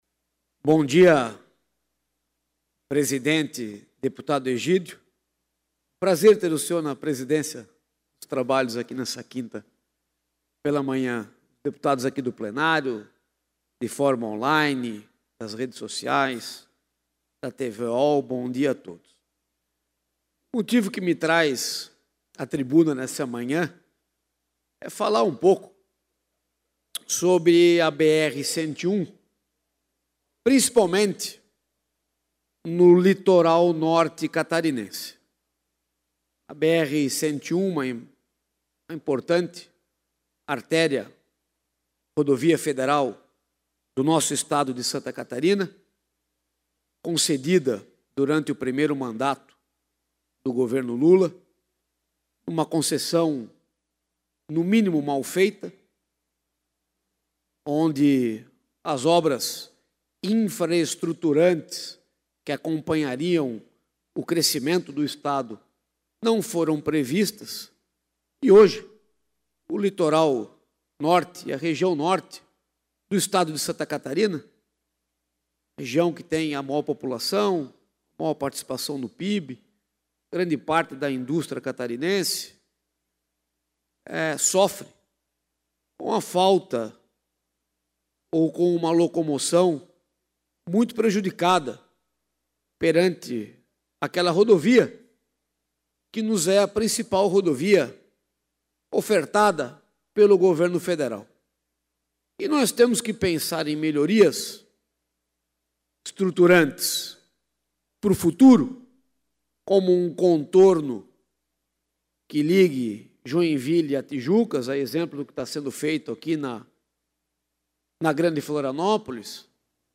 Pronunciamentos da sessão ordinária desta quinta-feira (16)